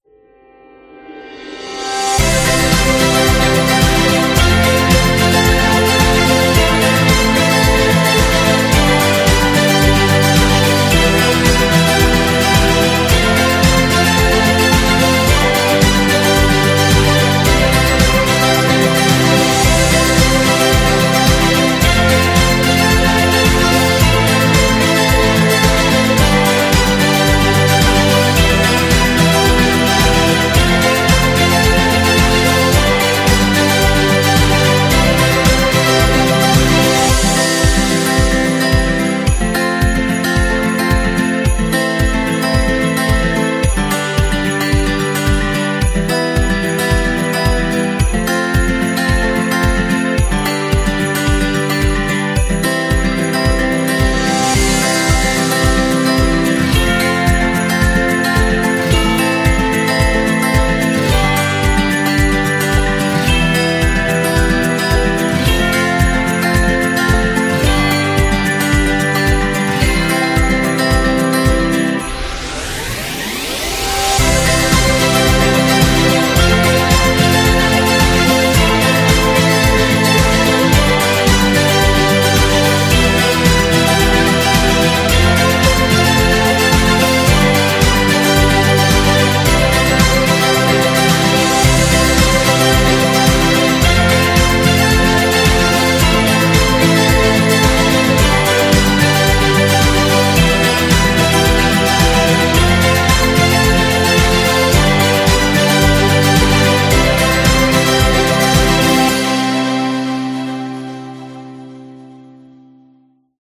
Uplifting, playful, and heartwarming